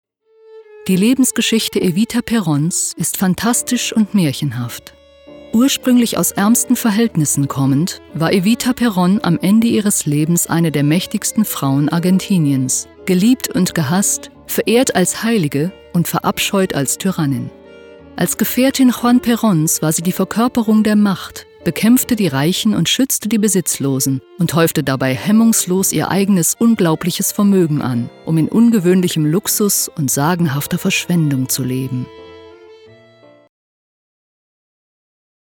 Mittel minus (25-45)
Ruhrgebiet
Eigene Sprecherkabine
Audioguide, Narrative, Scene